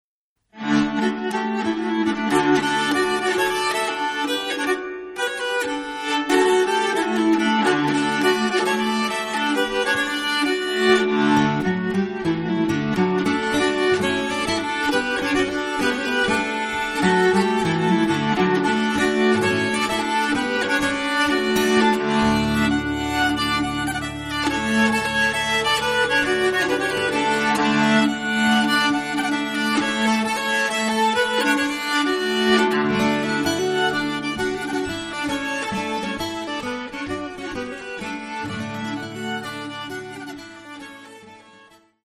Folkmusik rakt fram och med full speed…”